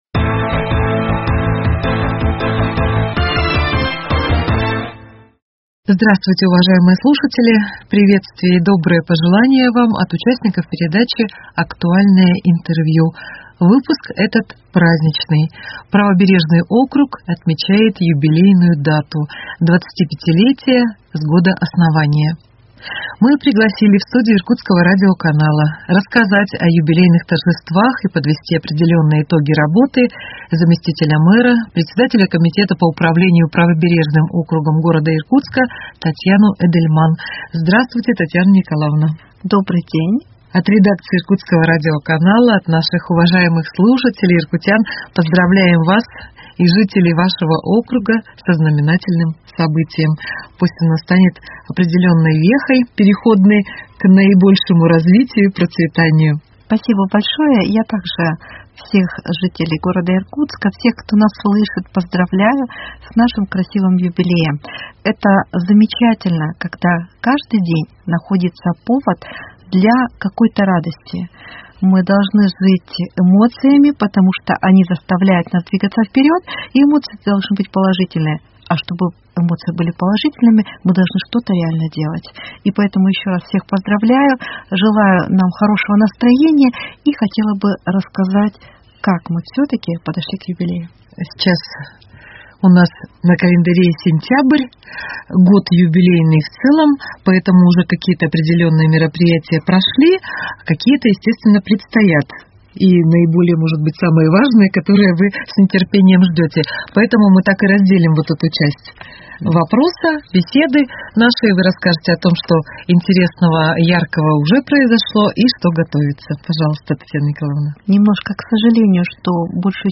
Актуальное интервью: 25 лет Правобережному округу 23.09.2021